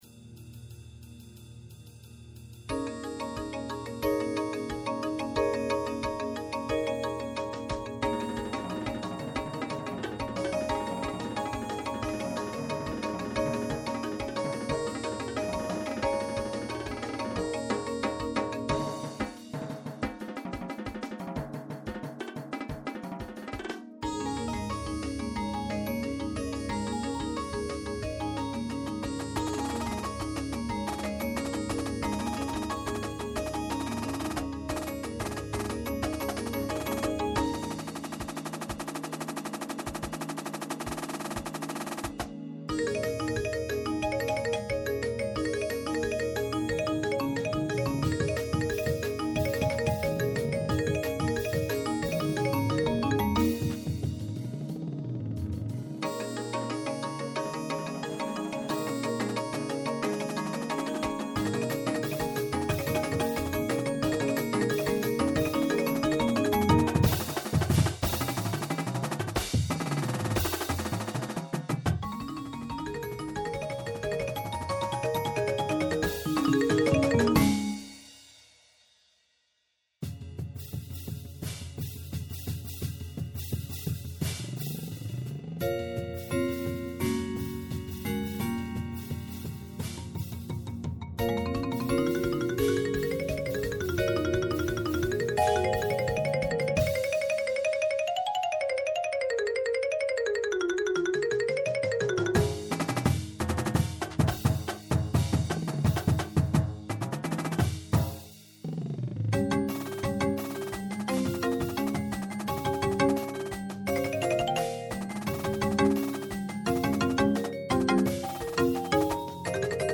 Bells
Xylophone
Marimba (3)
Vibes (2)
Synth
Electric Bass
Drum Set
Snare
Tenors (quints)
Bass Drums (5)
Cymbals